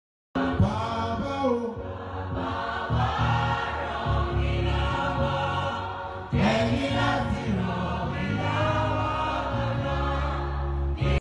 Nigeria Gospel Music
Contemporary Christian music singer